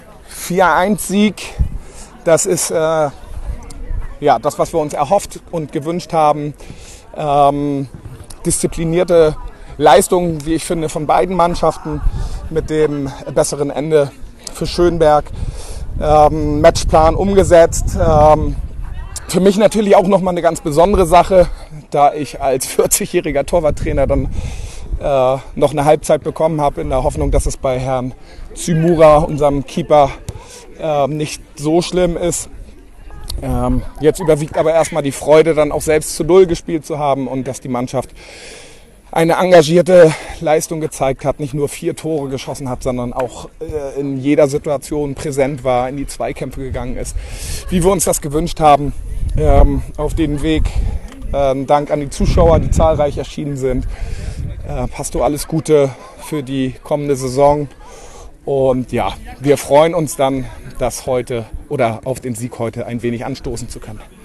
Stimmen zum Spiel